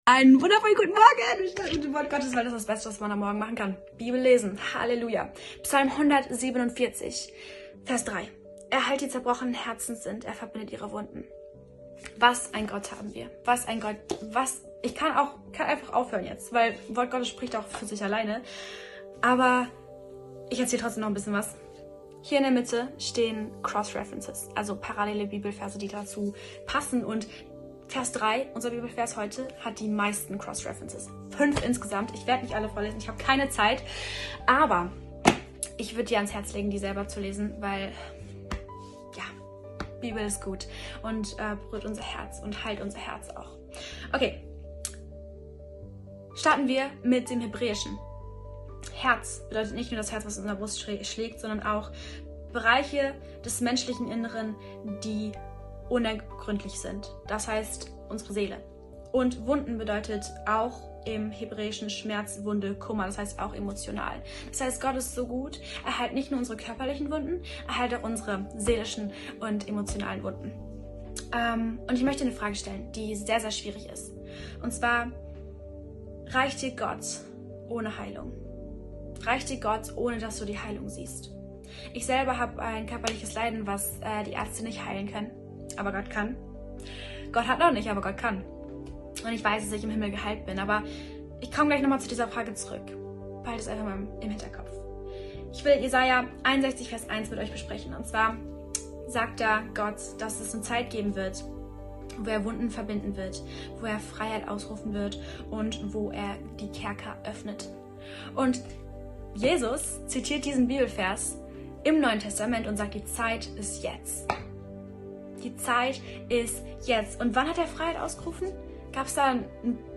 Tag 2 der Andacht zu unseren 21 Tagen Fasten & Gebet